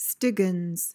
PRONUNCIATION: (STI-ginz) MEANING: noun: A pious impostor.